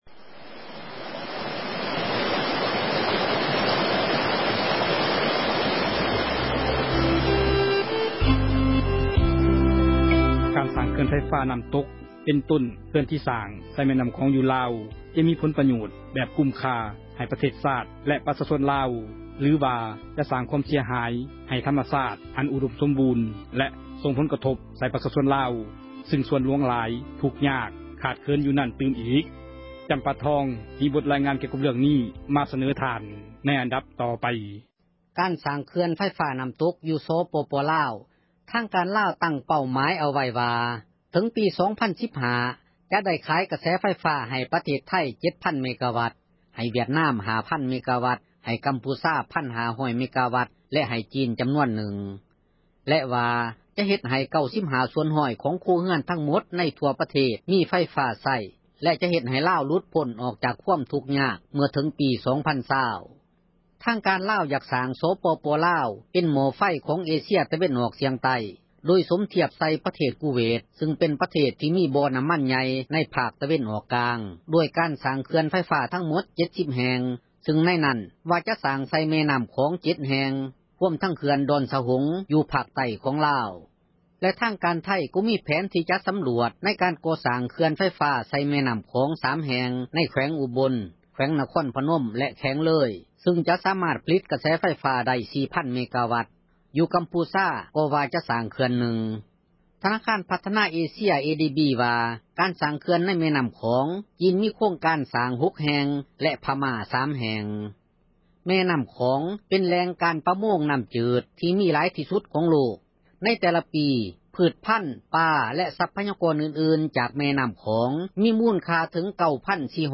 ມີບົດລາຍງານ ມາສເນີທ່ານ.